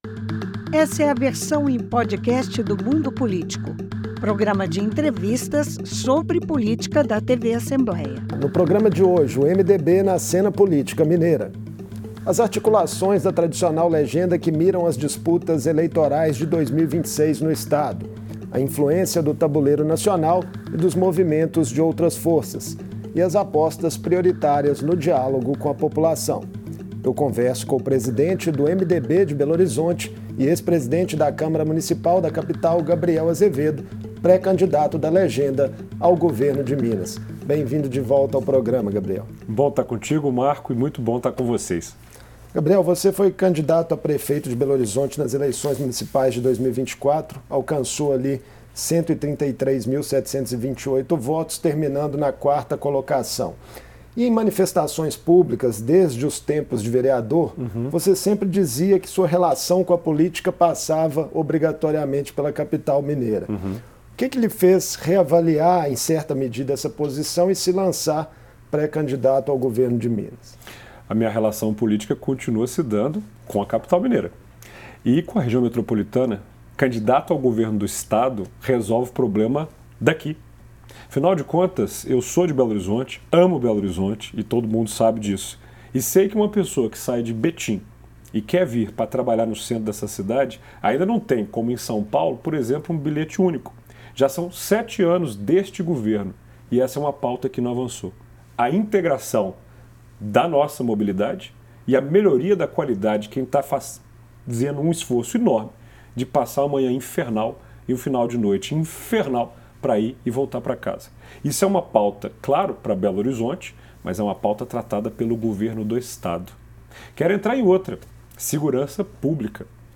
O presidente do MDB em Belo Horizonte e pré-candidato ao governo de Minas, Gabriel Azevedo, conversa